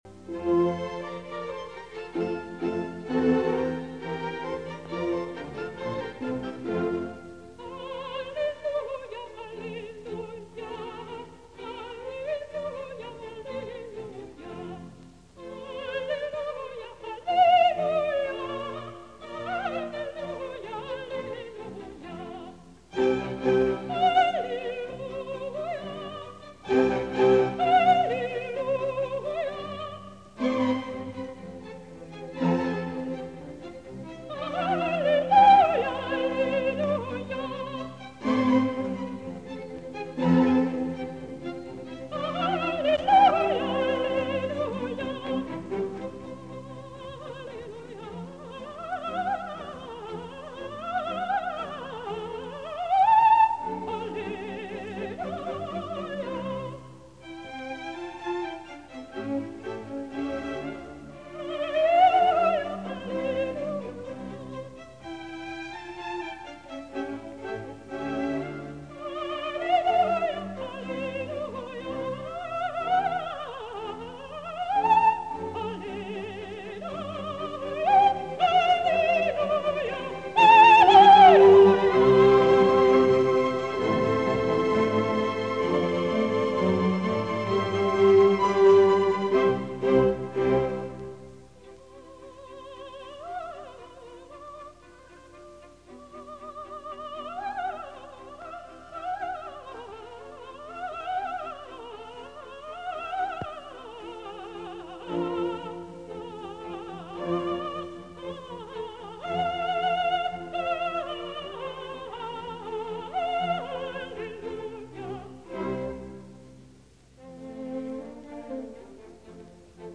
Concertgebouw Orchestra Amsterdam
Live recording Ria Ginster, soprano